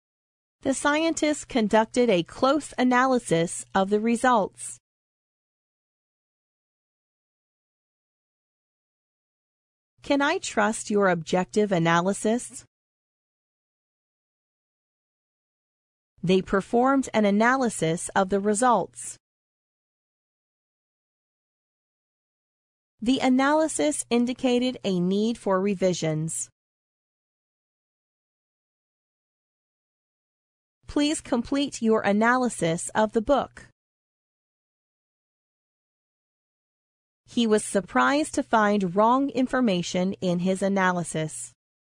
analysis-pause.mp3